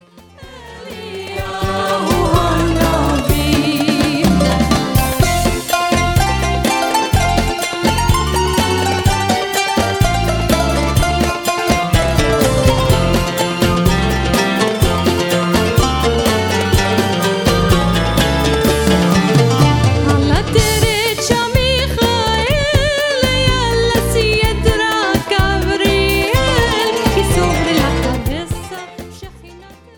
Recorded with top Israeli session players.